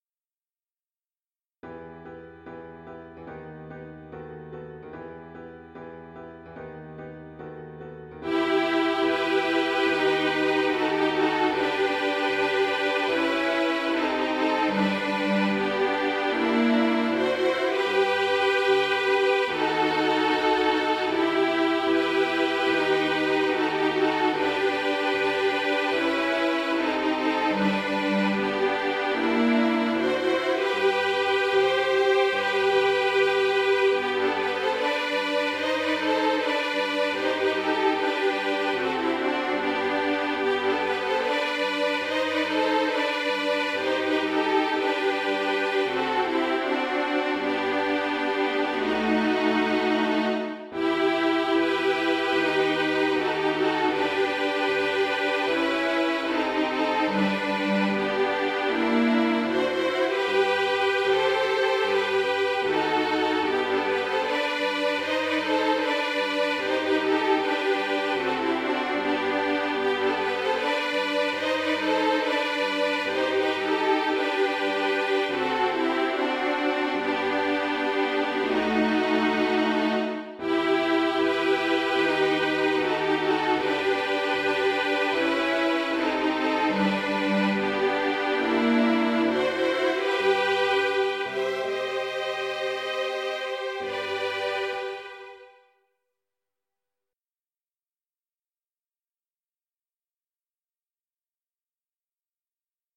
• Everyone together